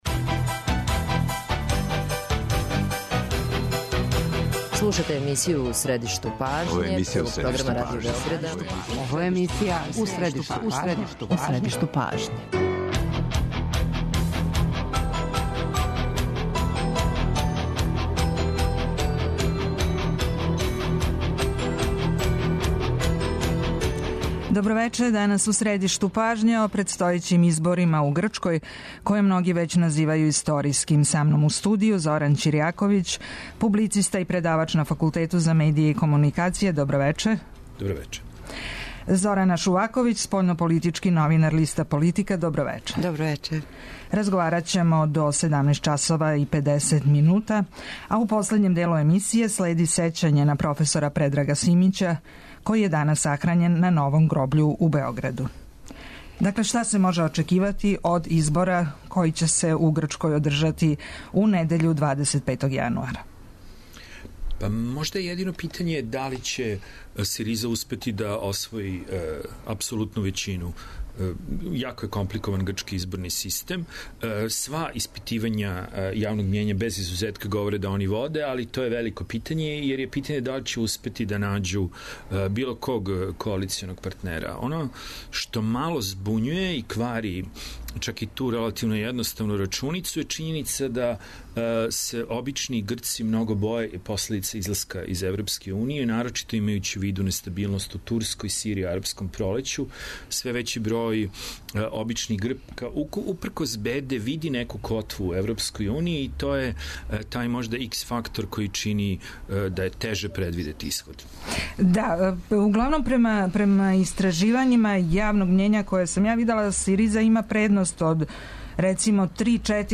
Путем телефона у разговор ће се укључити